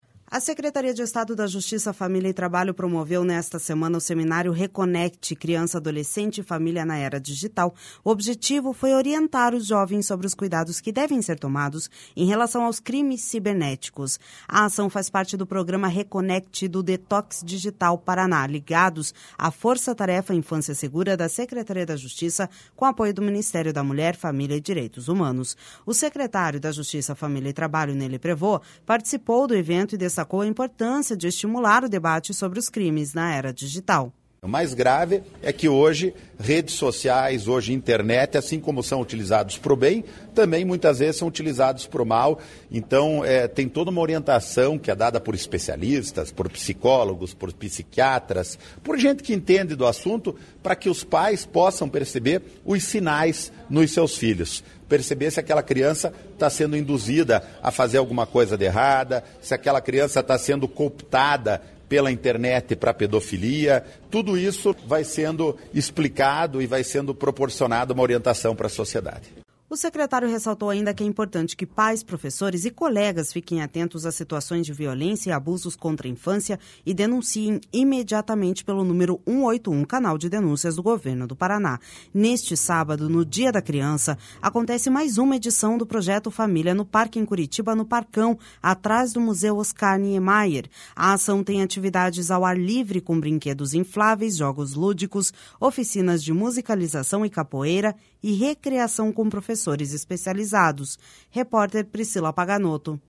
O secretário da Justiça, Família e Trabalho, Ney Leprevost, participou do evento e destacou a importância de estimular o debate sobre os crimes na era digital.// SONORA NEY LEPREVOST//O secretário ressaltou, ainda, que é importante que pais, professores e colegas fiquem atentos a situações de violência e abusos contra infância e denunciem imediatamente pelo número 181, o canal de denúncias do Governo do Paraná.